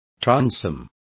Complete with pronunciation of the translation of transoms.